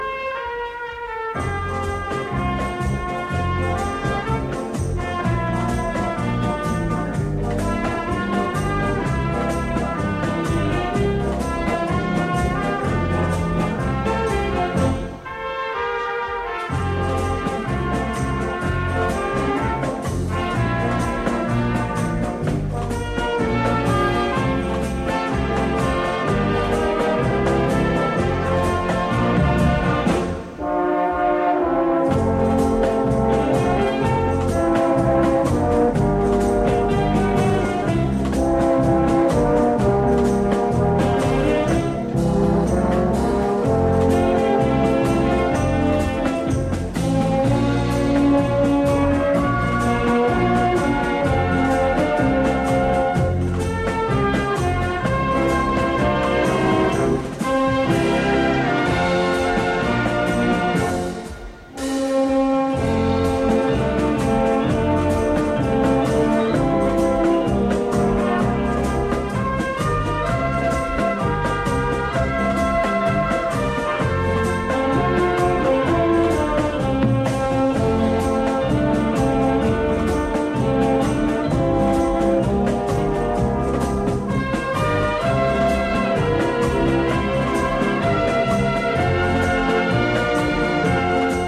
Gattung: Potpourri
Besetzung: Blasorchester
Teil 1: Im Beguine-Rhythmus; Teil 2: Im Slow-Rock-Rhythmus.
Für Blasorchester und Big-Band.